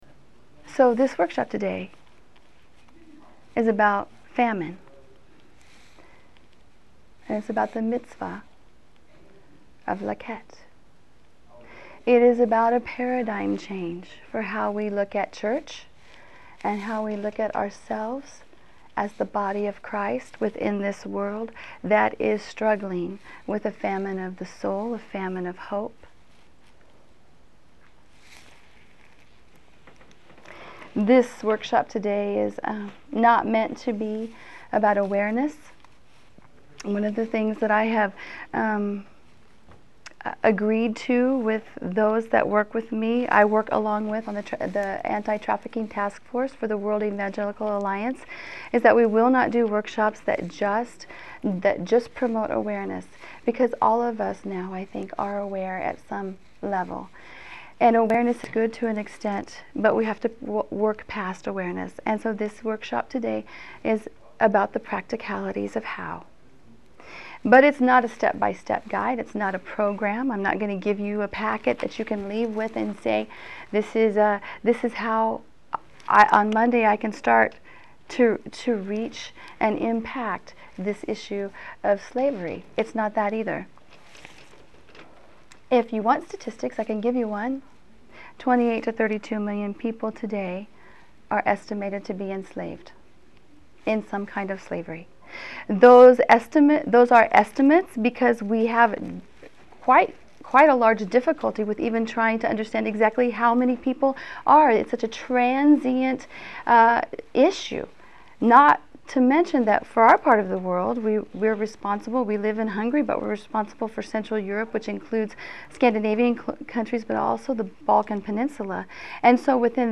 This workshop moves beyond the topic of awareness to explore the practical reality of a Church mobilized to end the issue of modern slavery: sexual trafficking, illegal labor, and organ harvesting. You will encounter a discussion that draws upon Wesleyan theology as the impetus for a practical and a hopeful response from the Church. From prevention to rehabilitation to re-entry, the workshop offers a broad context and history for effective and practical mobilization of the local church.